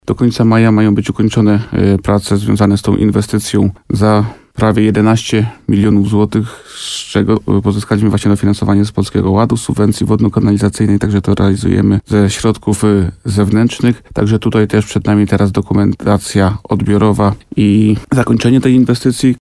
Jak mówił w programie Słowo za Słowo w RDN Nowy Sącz wójt gminy Łukowica Bogdan Łuczkowski, samorząd dąży w ten sposób do znacznej poprawy codziennego bytu mieszkańców.